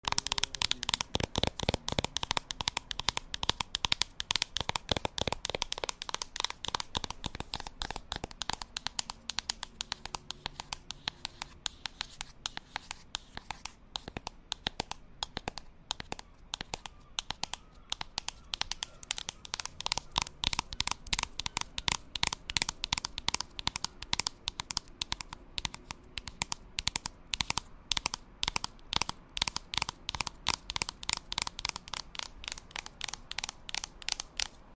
New ASMR video out soooo sound effects free download